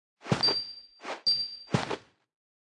Media:Sfx_Anim_Classic_Bo.wavMedia:Sfx_Anim_Super_Bo.wavMedia:Sfx_Anim_Ultra_Bo.wav 动作音效 anim 在广场点击初级、经典、高手和顶尖形态或者查看其技能时触发动作的音效
Sfx_Anim_Classic_Bo.wav